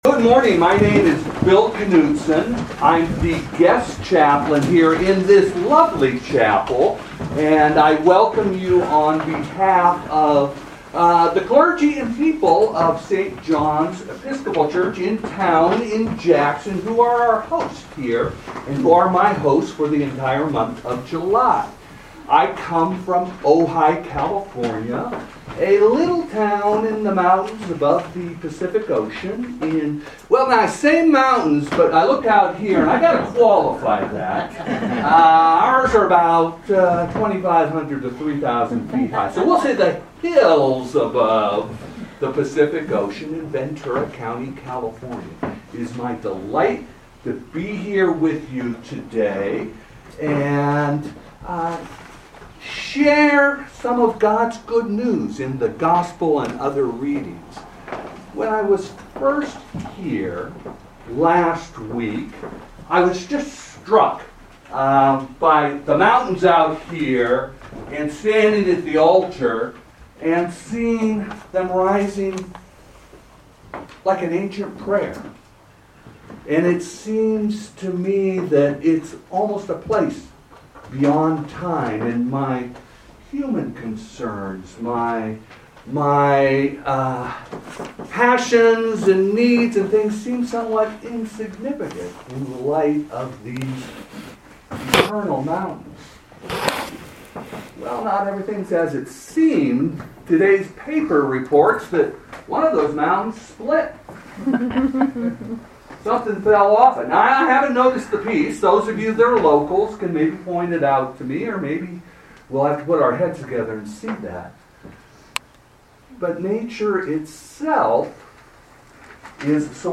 Proper 10 at Chapel of the Transfiguration
Sermons from St. John's Episcopal Church